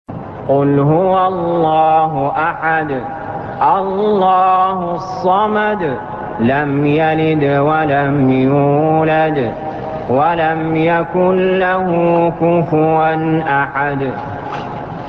المكان: المسجد الحرام الشيخ: علي جابر رحمه الله علي جابر رحمه الله الإخلاص